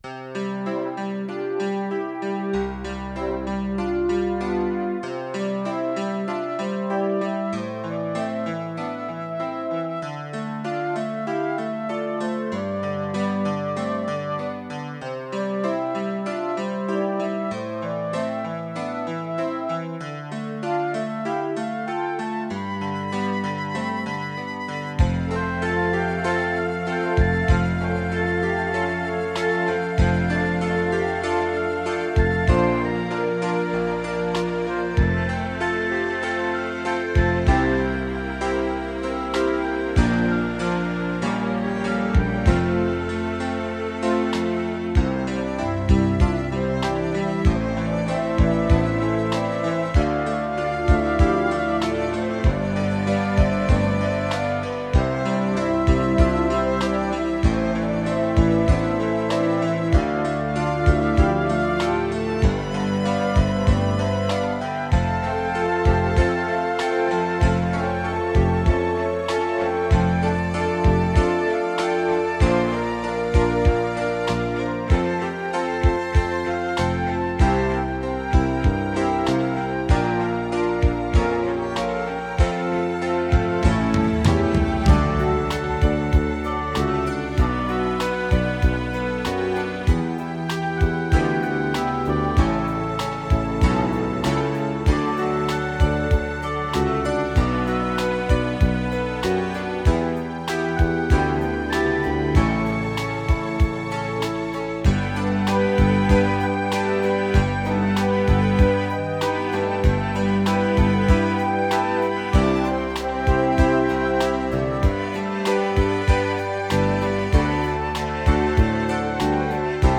De B-zijde was de instrumentale versie